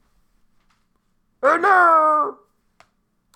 Oh no! Grommelot
Category 🗣 Voices
no oh voice walla sound effect free sound royalty free Voices